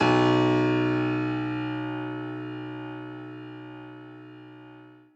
admin-fishpot/b_basspiano_v100l1-4o2b.ogg at main